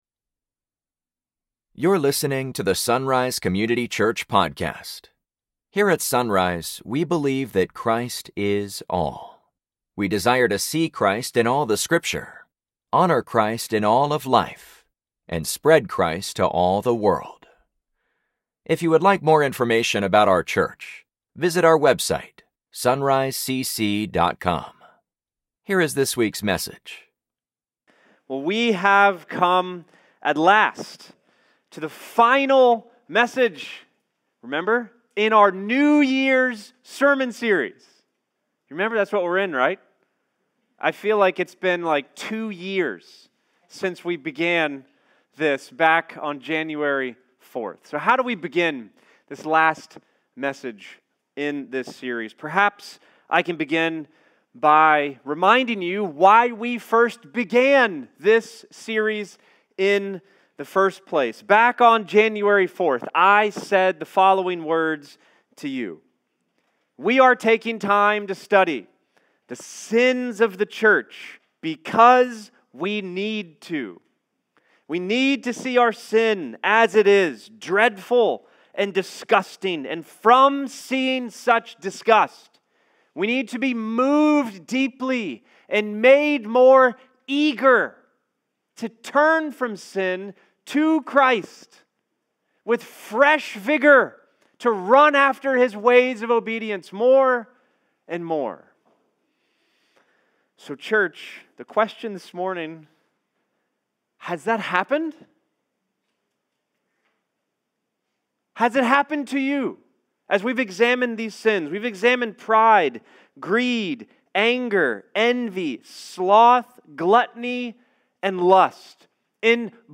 Sunday Mornings | SonRise Community Church
After seven weeks we have finally come to the last message in our new year sermon series.